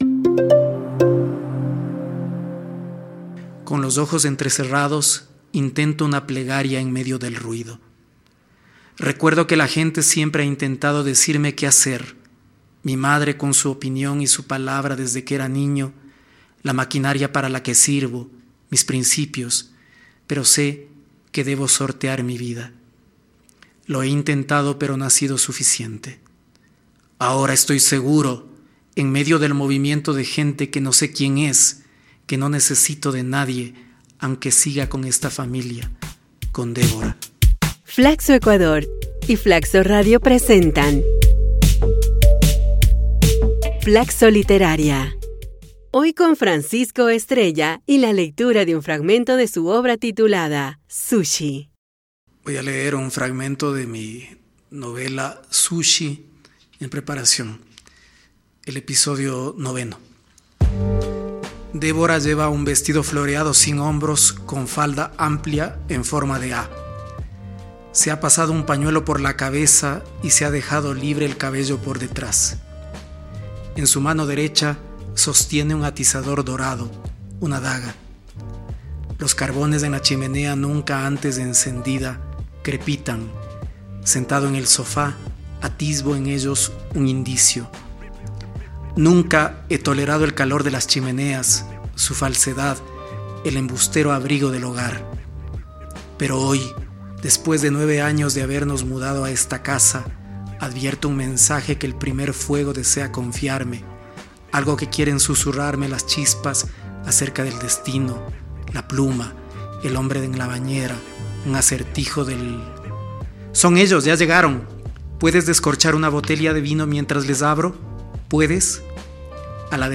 FLACSO Literaria es una iniciativa de lectura de cuentos y fragmentos de novelas que busca promover la literatura ecuatoriana, que reúne a 21 destacados escritores y escritoras quienes leerán sus obras.